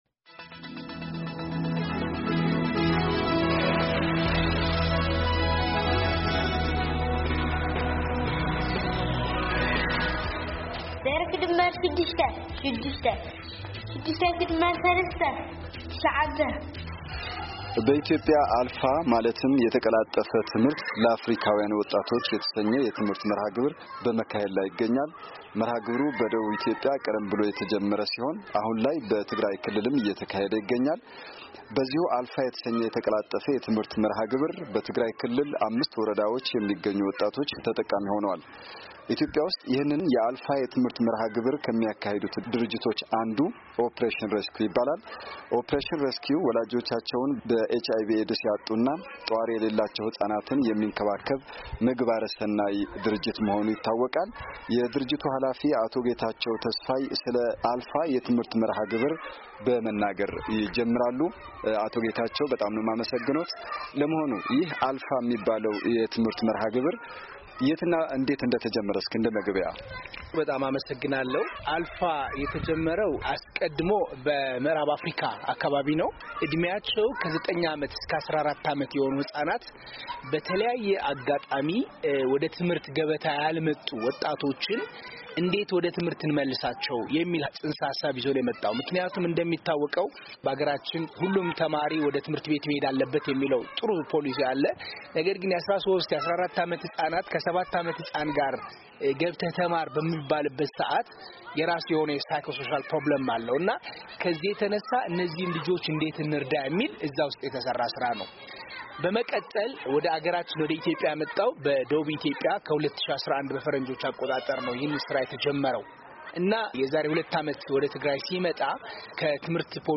ከአሜሪካ ድምጽ ሬድዮ ጋር ባደረጉት ቃለ ምልልስ ገልጸዋል።